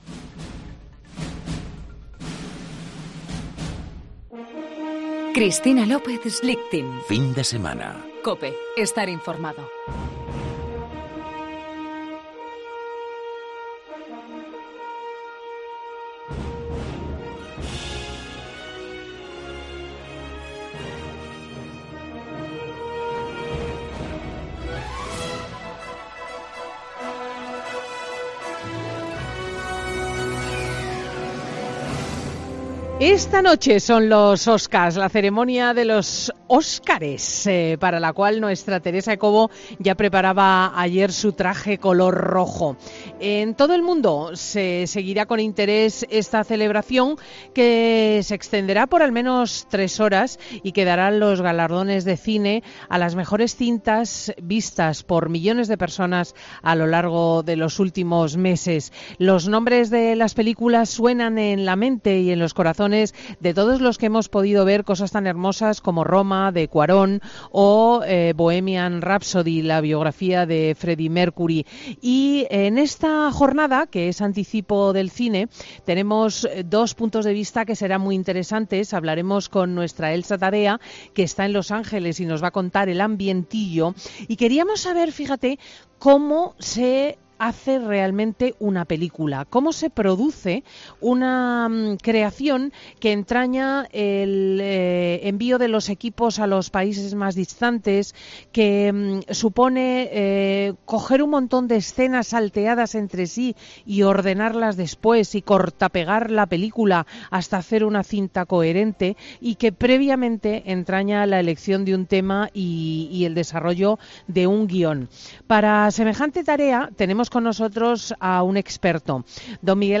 Para darnos todas las claves ha visitado los estudios de Fin de Semana